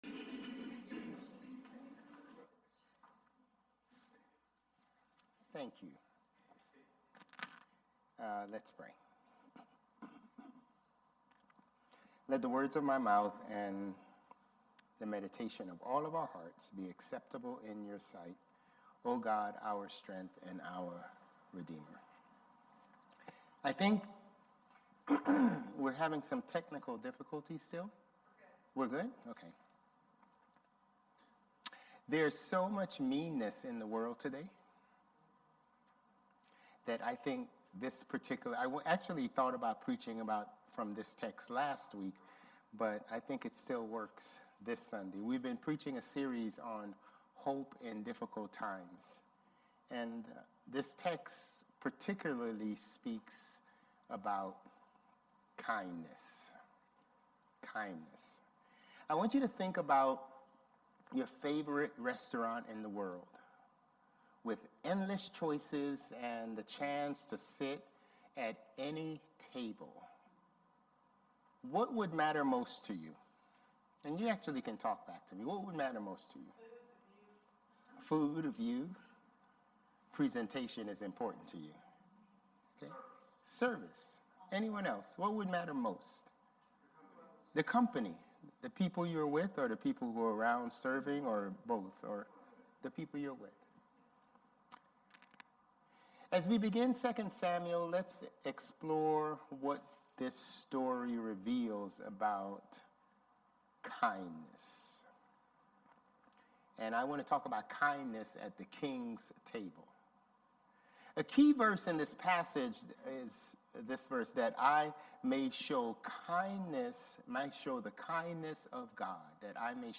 Due to some technical transmission quality issues with Zoom, the opening songs are missing from this week's service.